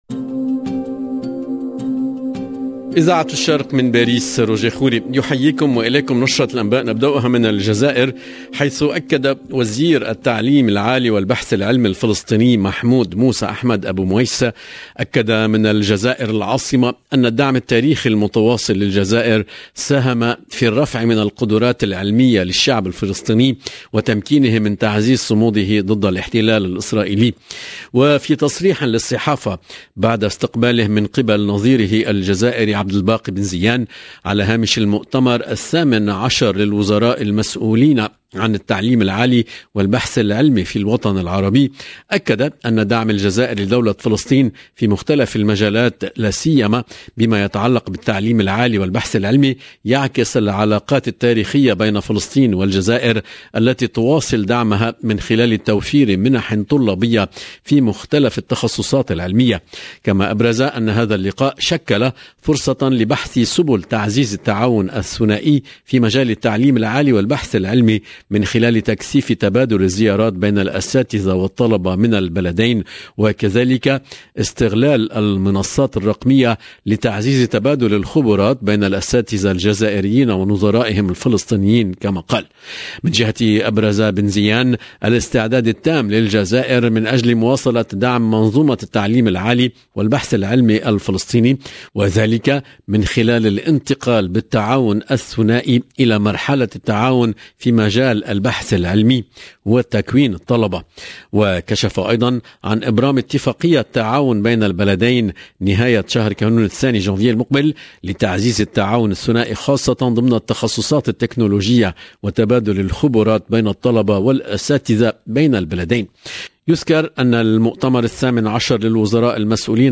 LE JOURNAL DU SOIR EN LANGUE ARABE DU 27/12/21